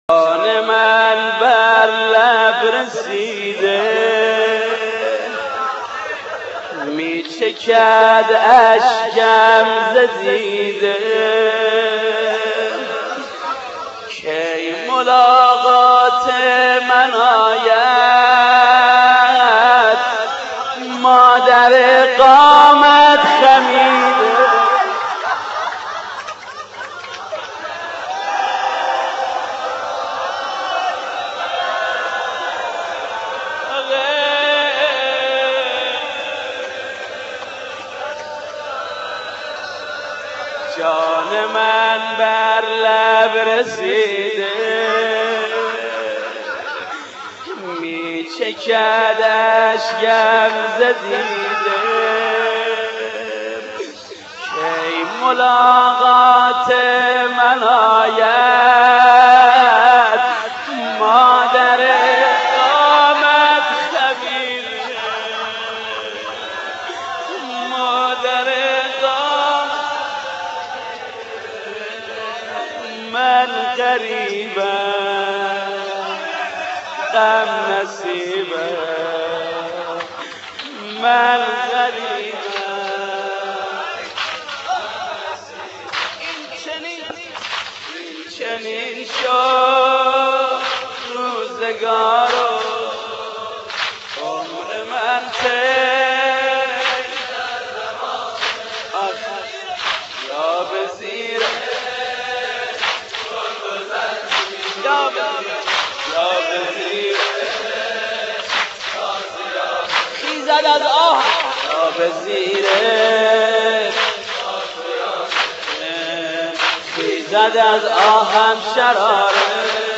• مداحی
شهید علمدار و روضه حضرت زهرا
فاطمیه 94